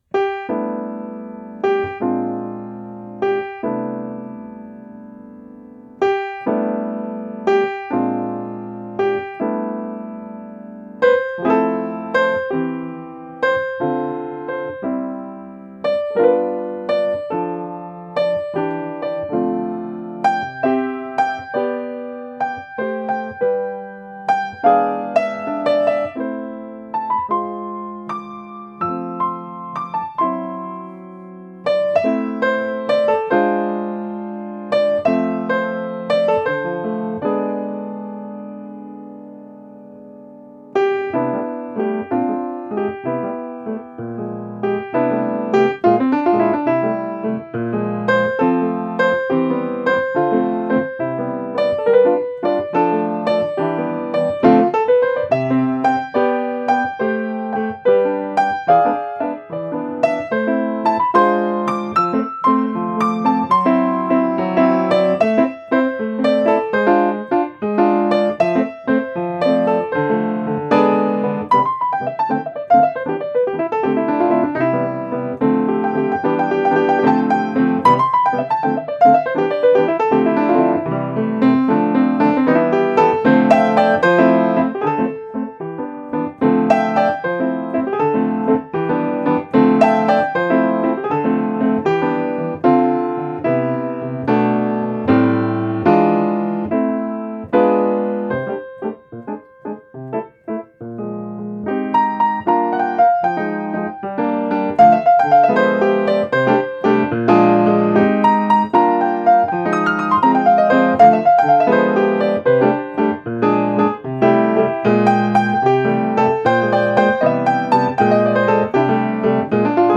solo on acoustic Yamaha grand piano.